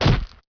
gen_hit7.wav